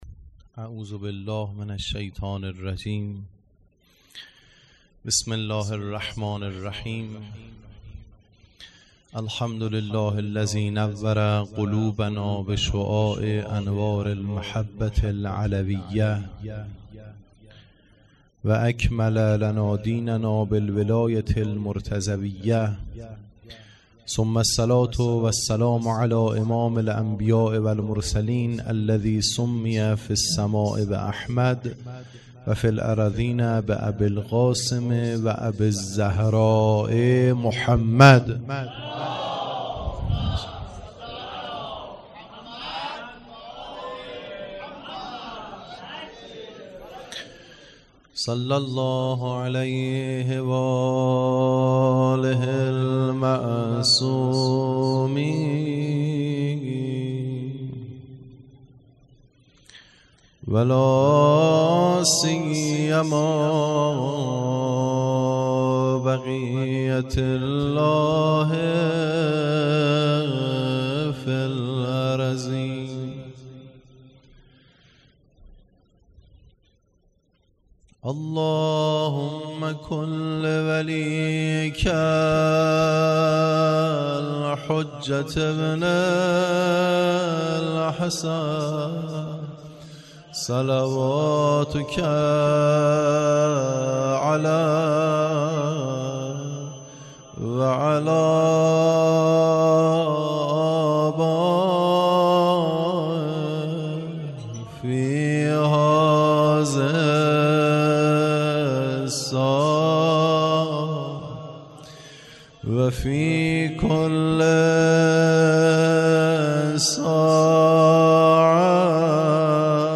مراسم عزاداری محرم ۱۴۰۲